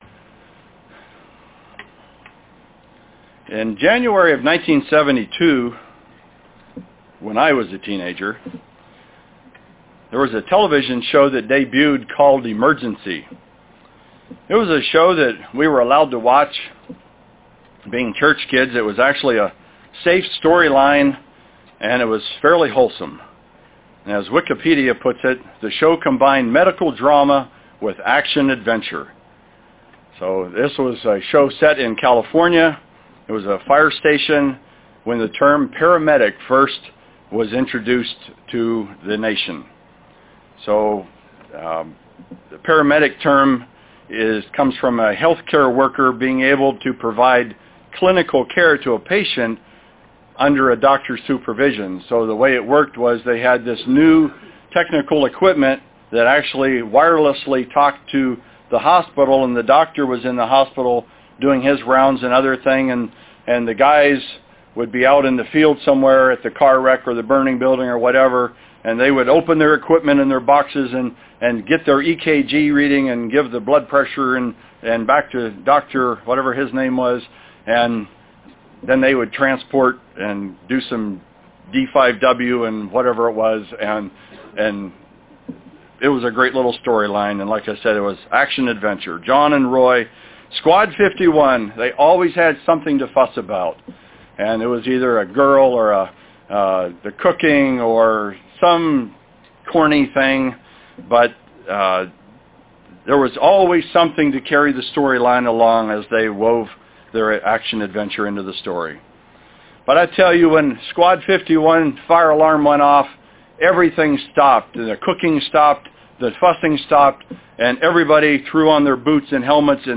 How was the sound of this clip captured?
Given in Kennewick, WA Chewelah, WA Spokane, WA